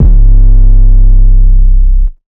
808 - 20 Min [ C ].wav